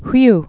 (hwy, hw)